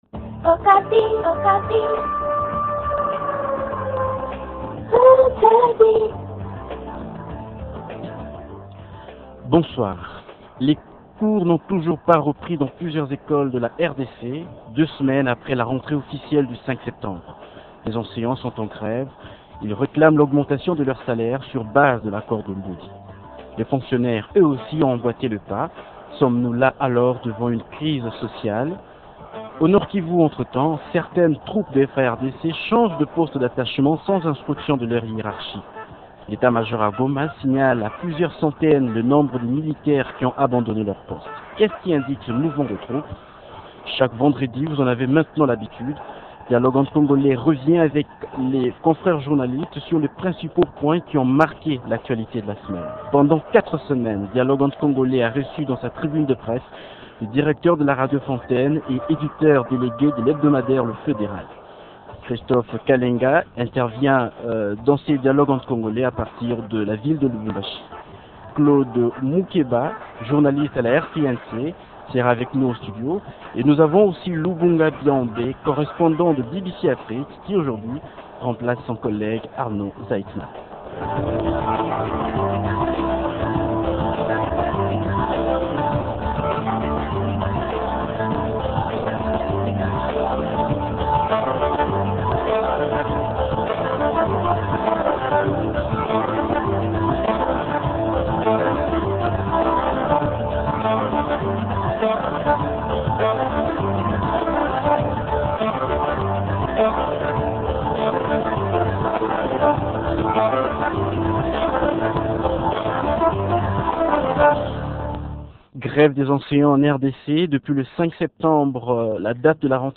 Deux thèmes à débattre ce soir :